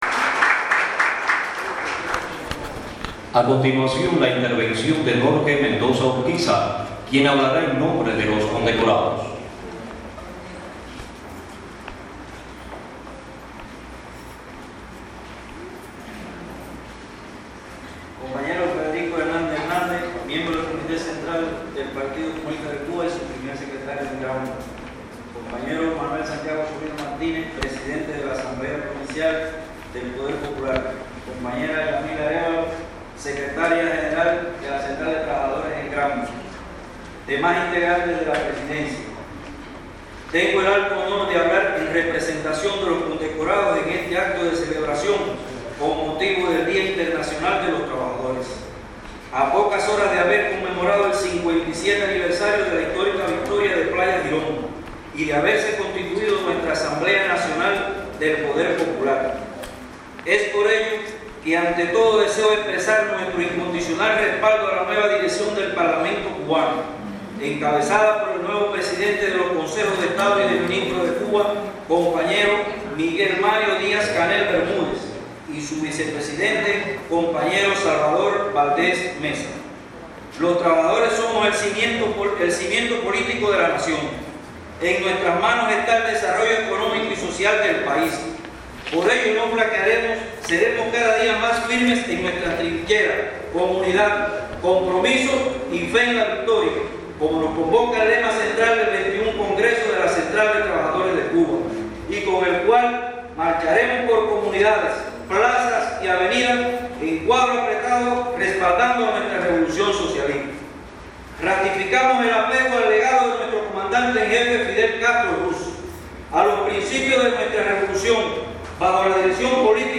Enarbolando el lema Unidad, compromiso y victoria, se efectuó este sábado el acto de condecoraciones previo al Día Internacional del proletariado, para rendir homenaje a hombres y mujeres que como verdaderos revolucionarios no han escatimado esfuerzos en el cumplimiento de sus deberes.
El Salón de Protocolo de la Plaza de la Patria de Bayamo, fue la sede para homenajear en solemne acto a 42 trabajadores de la provincia de Granma, como parte del plan de actividades por el Primero de Mayo.